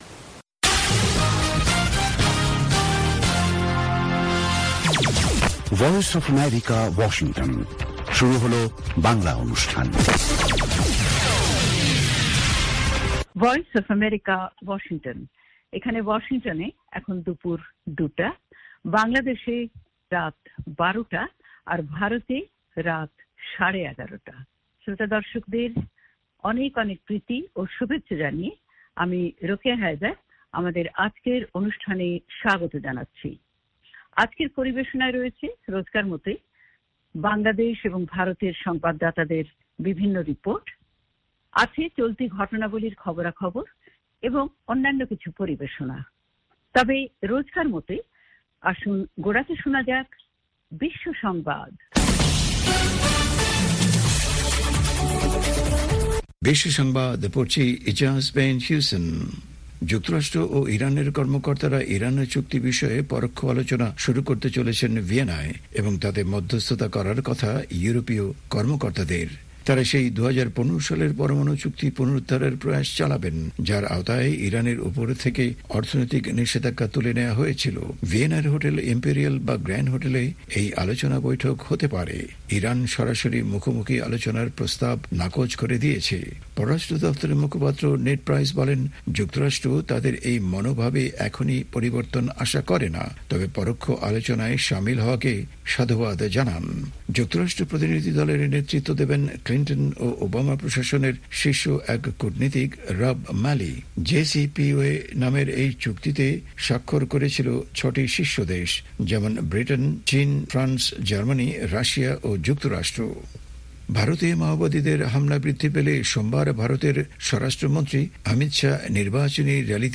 অনুষ্ঠানের শুরুতেই রয়েছে আন্তর্জাতিক খবরসহ আমাদের ঢাকা এবং কলকাতা সংবাদদাতাদের রিপোর্ট সম্বলিত বিশ্ব সংবাদ, বুধবারের বিশেষ আয়োজন হ্যালো ওয়াশিংটন। আর আমাদের অনুষ্ঠানের শেষ পর্বে রয়েছে যথারীতি সংক্ষিপ্ত সংস্করণে বিশ্ব সংবাদ।